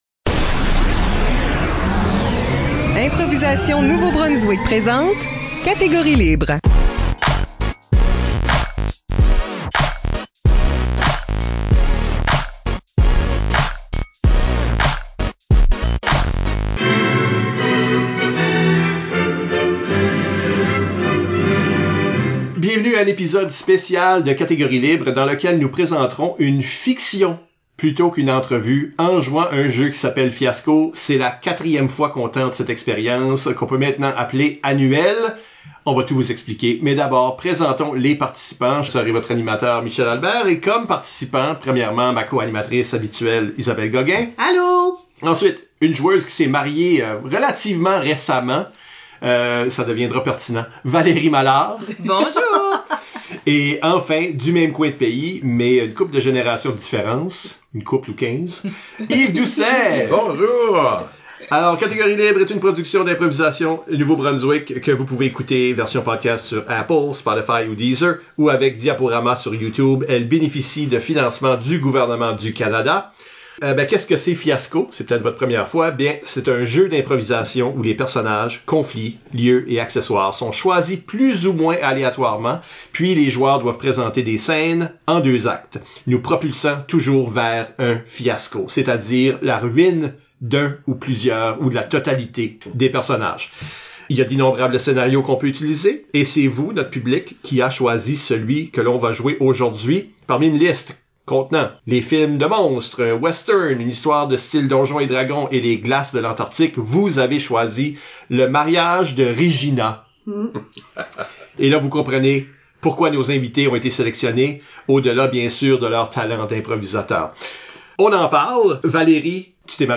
Nos quatre participants prennent les rôles de Regina et les membres de son cortège nuptial dans un scénario (en anglais) que vous pouvez trouver ICI (avec achat du Fiasco Companion).